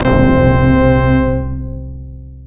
X CRESC FX.wav